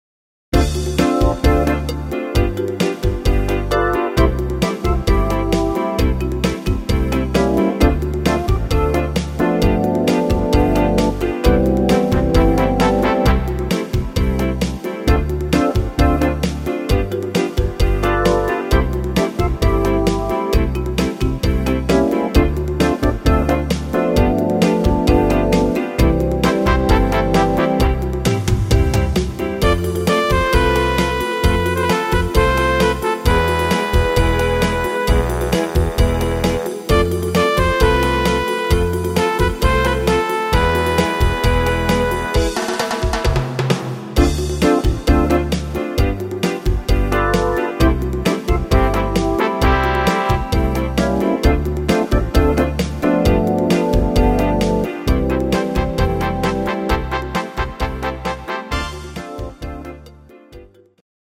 instr. Combo